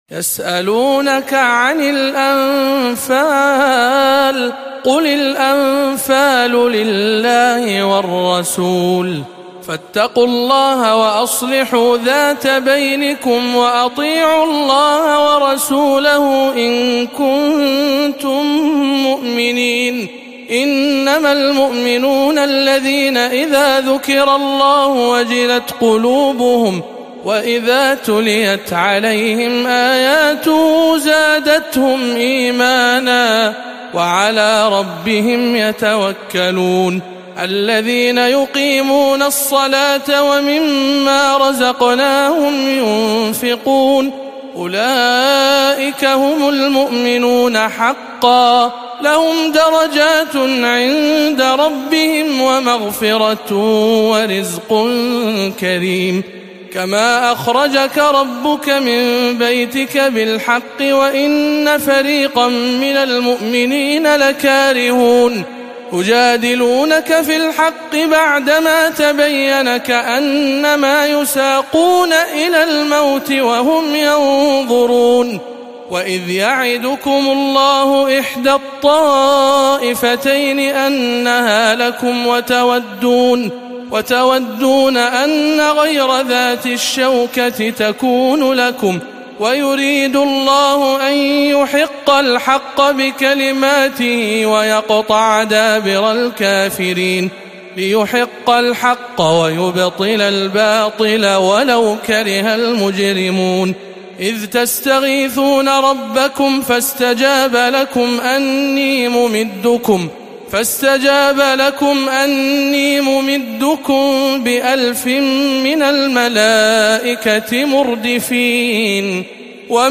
06. سورة الأنفال بجامع أم الخير بجدة - رمضان 1438 هـ